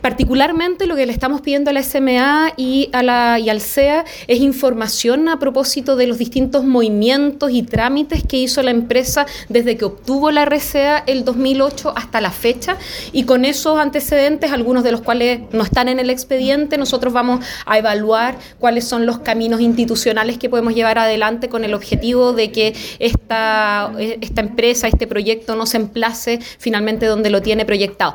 En conversación con Radio Bío Bío, la alcaldesa mencionó que han pasado casi 17 años desde que se aprobó la RCA y en esa fecha ni siquiera existía el Ministerio de Medio Ambiente ni el Servicio de Evaluación Ambiental, por lo que la resolución que está vigente no le da confianza.